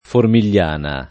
[ formil’l’ # na ]